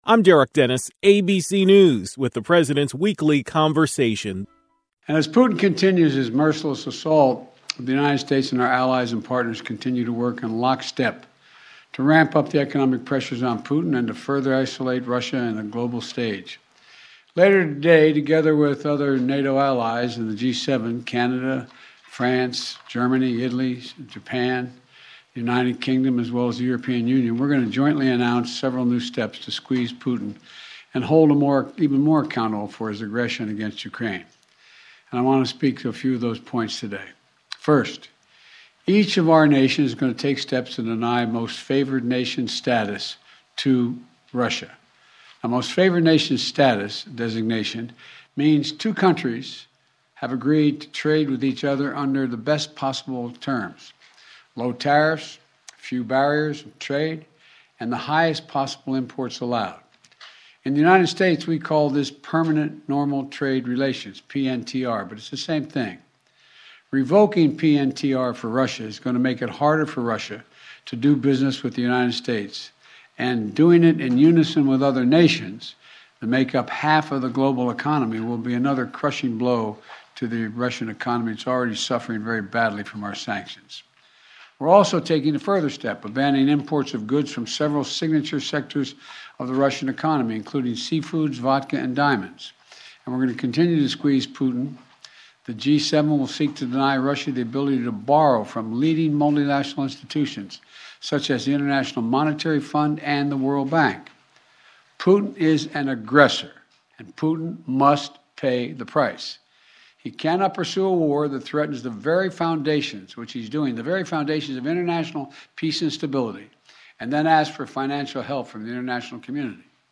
Here are his words: